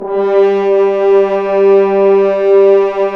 Index of /90_sSampleCDs/Roland LCDP06 Brass Sections/BRS_F.Horns 2 _/BRS_FHns Dry _